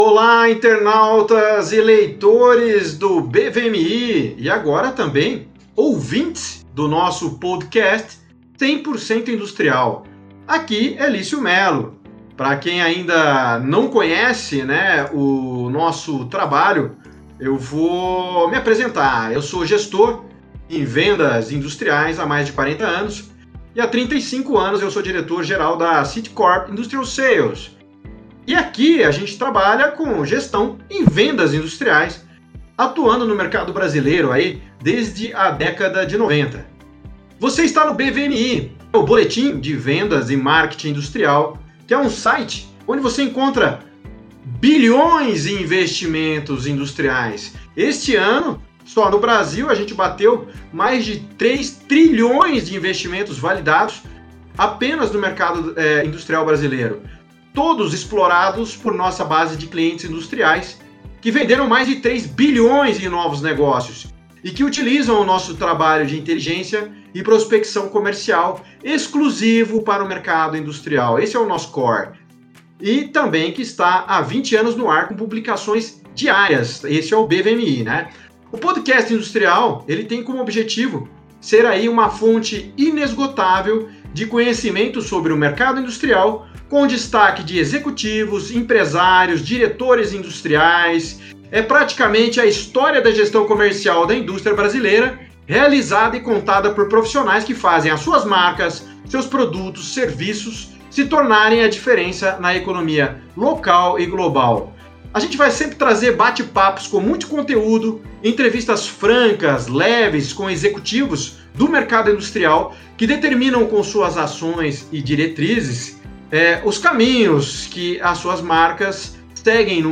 São bate papos com muito conteúdo, entrevistas francas com executivos industriais que determinam com suas ações as diretrizes do mercado industrial brasileiro, sempre a partir da perspectiva de suas estratégias e ações comerciais.